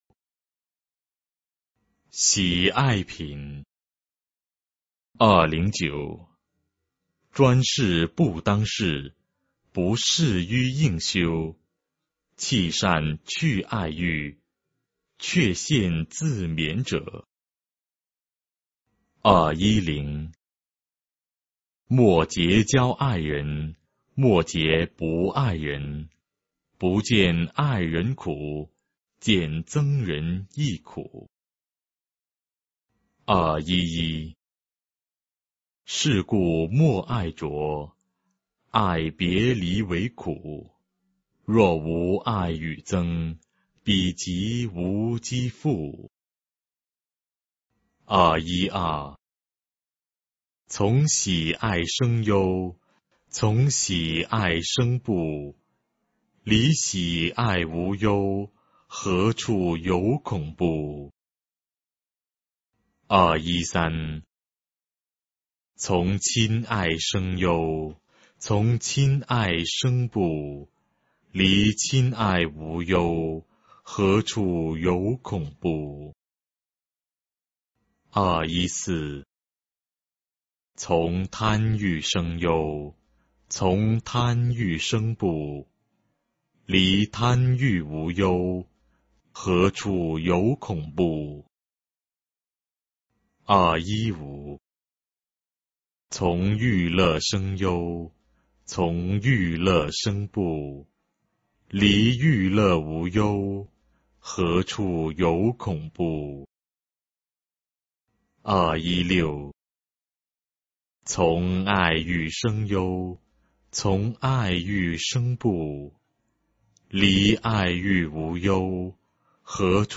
法句经-喜爱品 - 诵经 - 云佛论坛